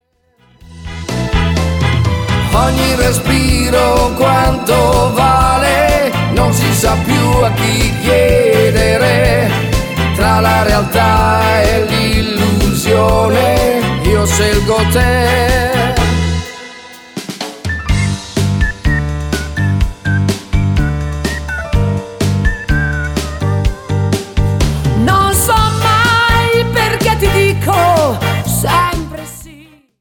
MODERATO  (5.46)